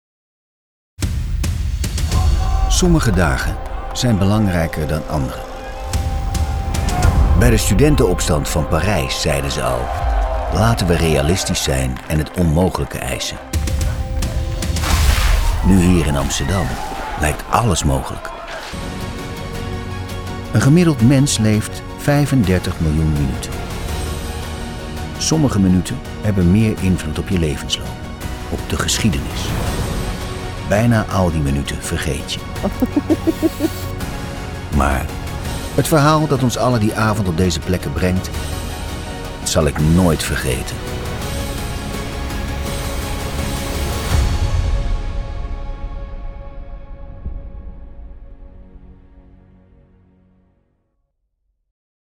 Voice Demos
marcel-hensema-trailer-lvj---no19-talent-management.m4a